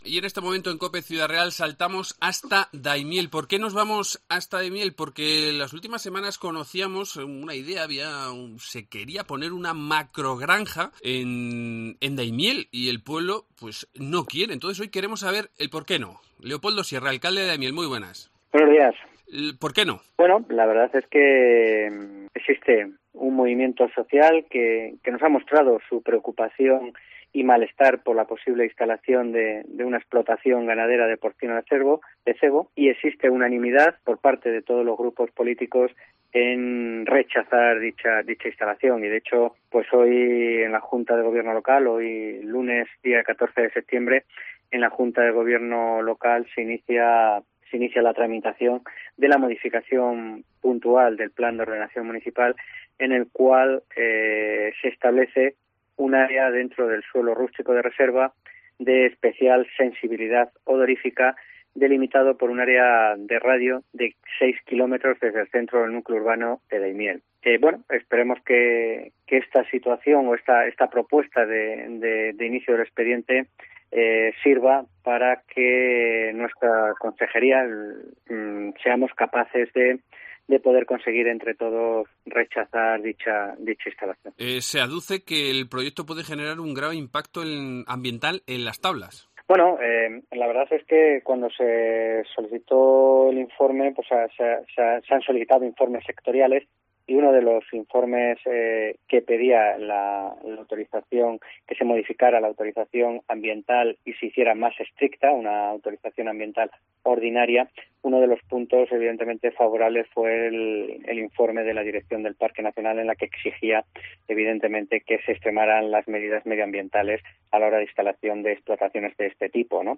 Leopoldo Sierra, alcalde de la localidad